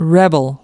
audios / us / rebel-noun.mp3
rebel-noun.mp3